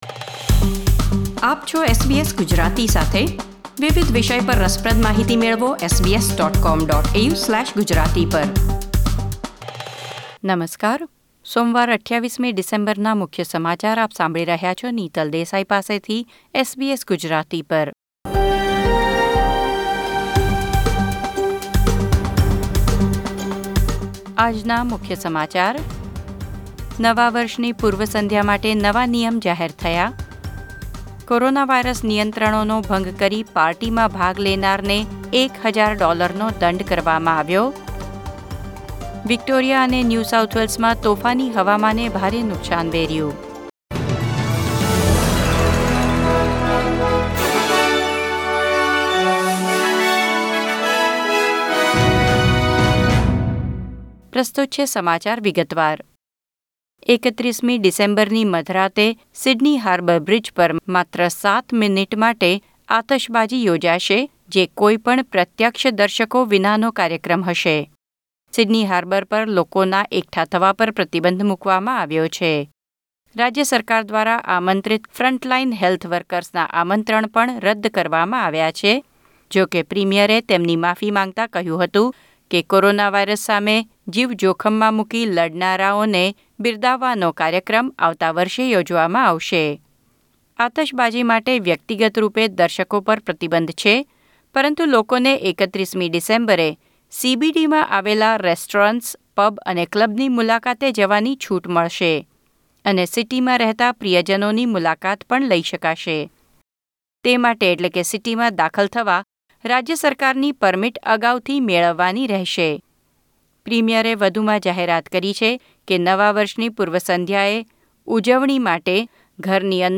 SBS Gujarati News Bulletin 28 December 2020